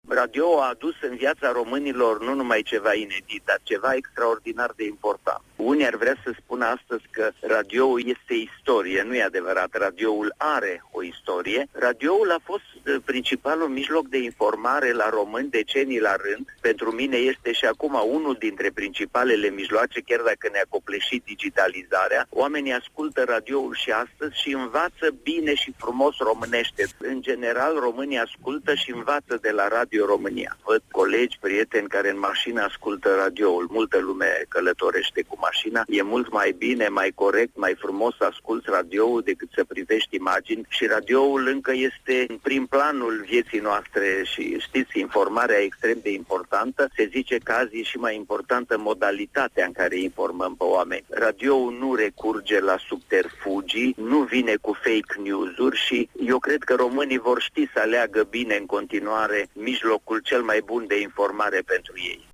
Momentul a fost evocat şi de Preşedintele Academiei Române, istoricul Ioan-Aurel Pop, în cadrul emisiunii Matinal, de la Radio România Actalităţi.